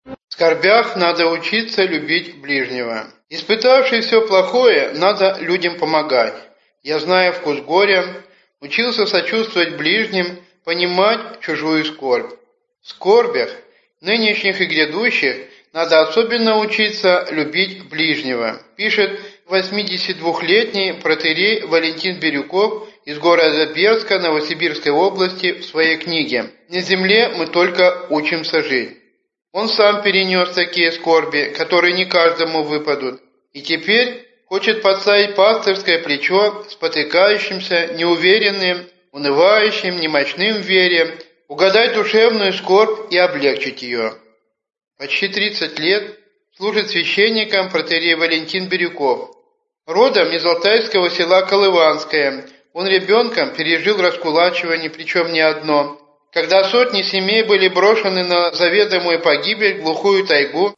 Аудиокнига На земле мы только учимся жить | Библиотека аудиокниг